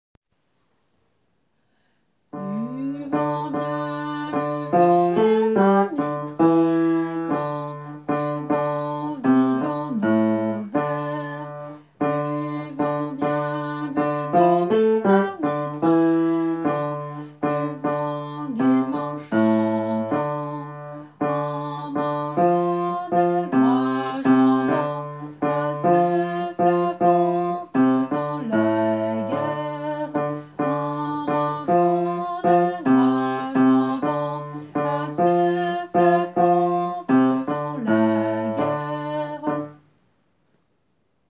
Basse :
tourdion-bassus.wav